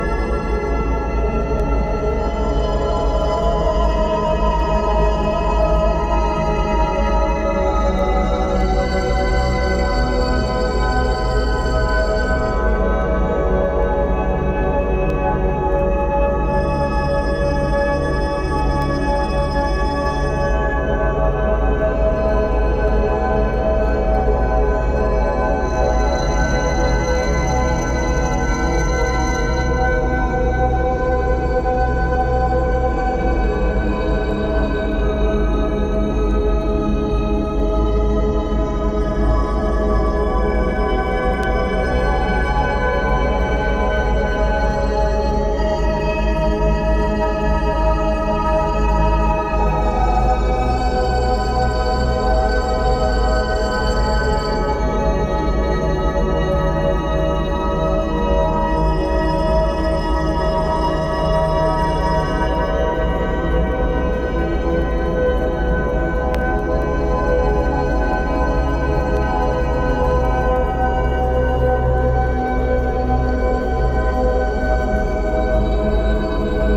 ゲーム音楽、ヴェイパーウェイヴ、テクノ等様々な要素を見事にHip Hopに落とし込んだアルバム。